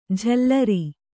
♪ jhallari